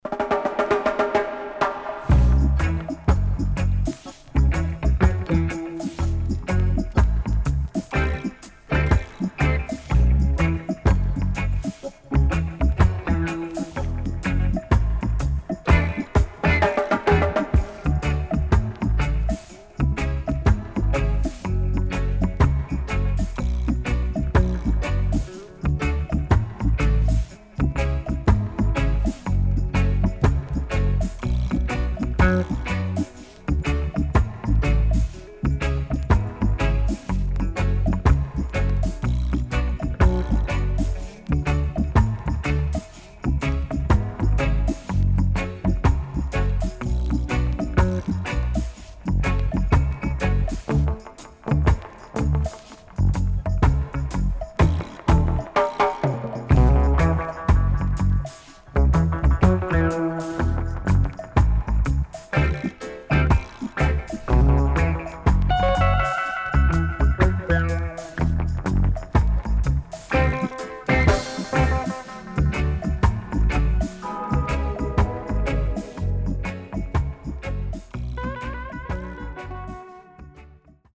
SIDE A:盤質は良好です。